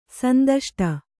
♪ sandaṣṭa